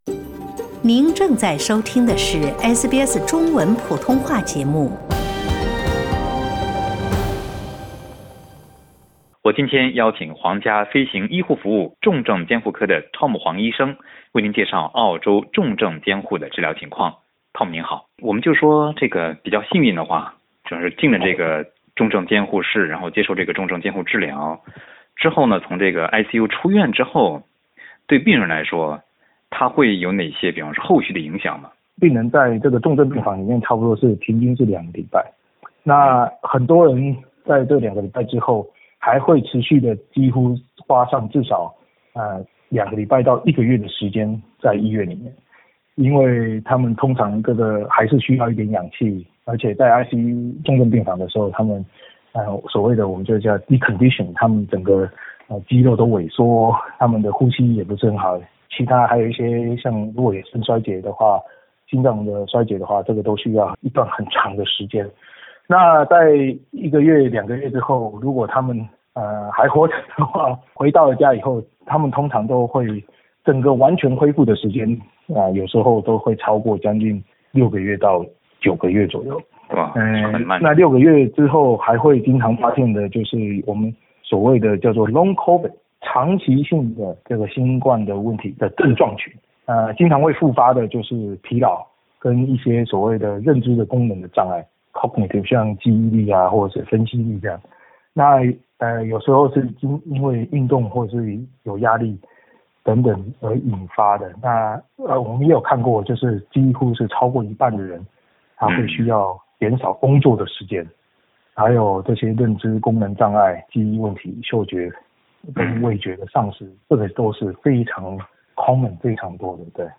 在采访中